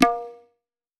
Index of /musicradar/essential-drumkit-samples/Hand Drums Kit
Hand Tabla 03.wav